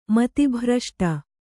♪ mati bhraṣṭa